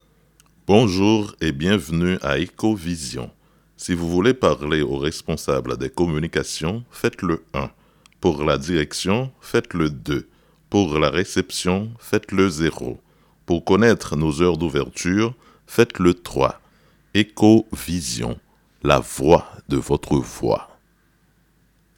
Si vous êtes à la recherche de voix gutturale, énergique, imposante, timbrée ayant une parfaite résonance, vous êtes sur la bonne voie/voix.
Démo
Messagerie-daccueil-telephonique.mp3